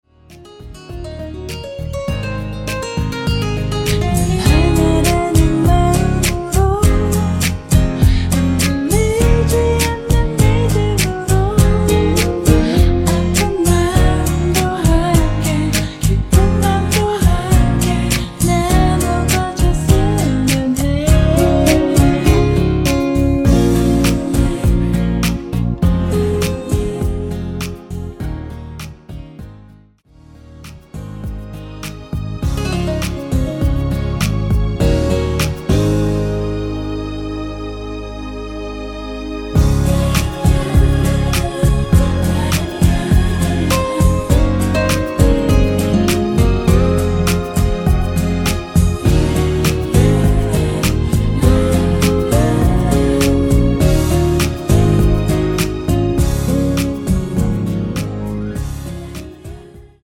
Db
◈ 곡명 옆 (-1)은 반음 내림, (+1)은 반음 올림 입니다.